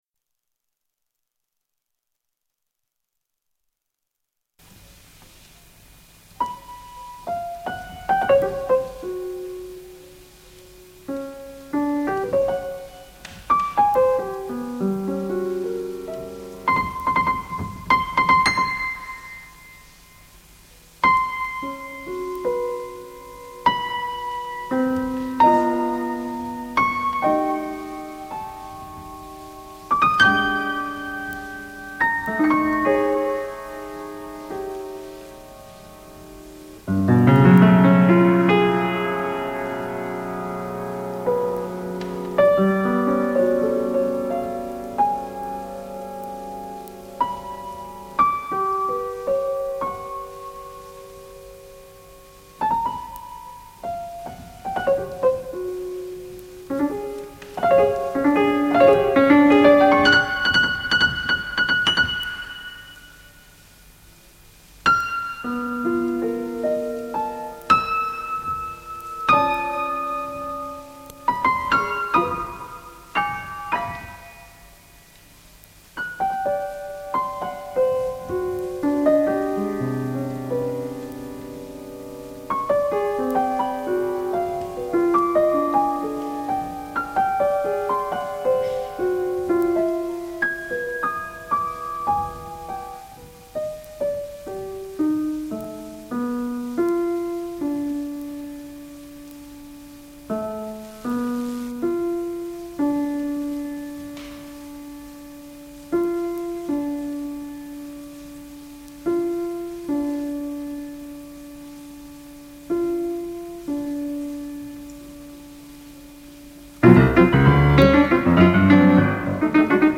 für Klavier, 6'30"